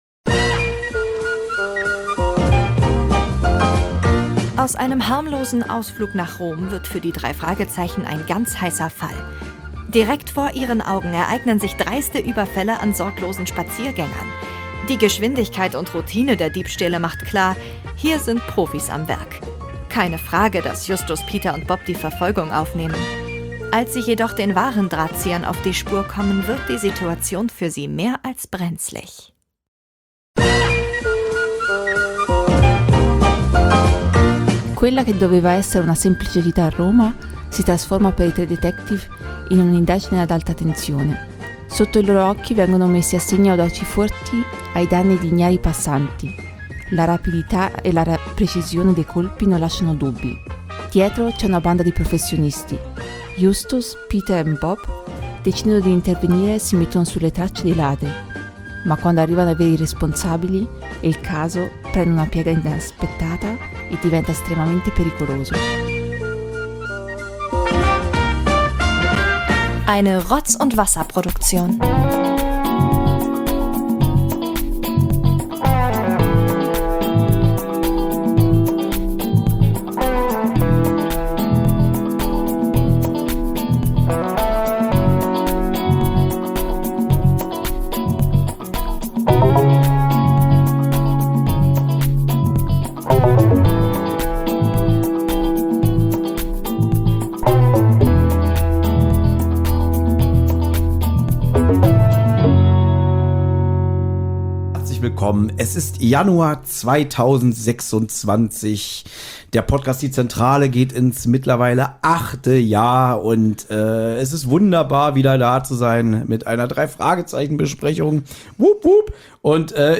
die drei Podcaster